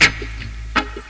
RIFFGTR 20-R.wav